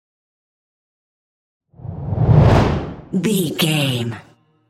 Dramatic whoosh to hit trailer
Sound Effects
dark
intense
tension
woosh to hit